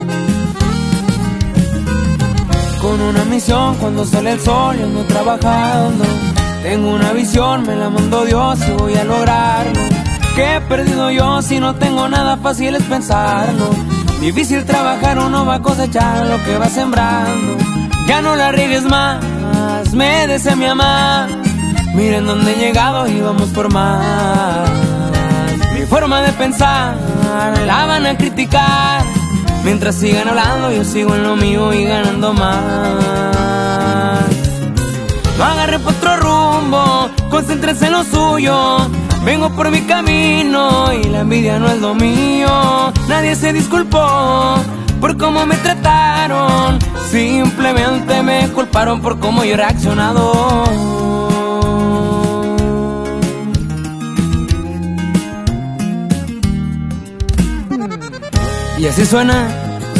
Just a work truck doing sound effects free download